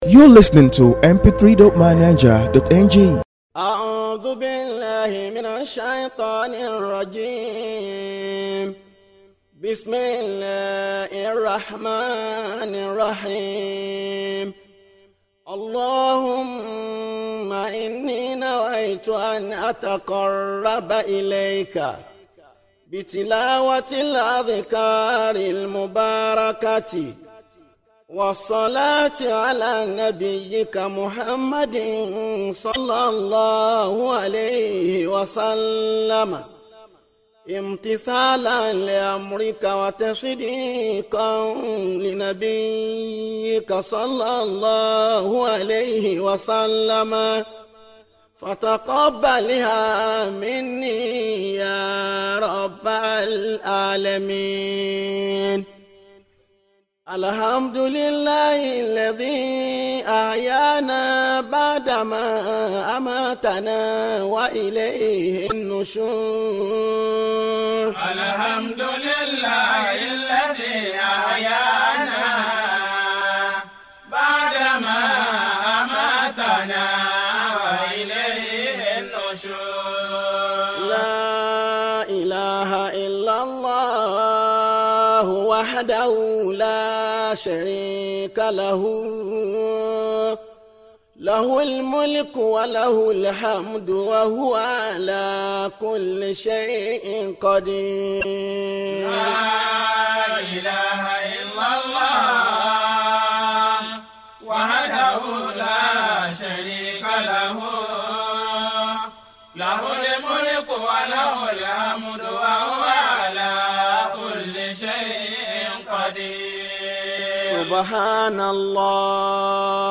ISTIQILAL ASALATU PRAYER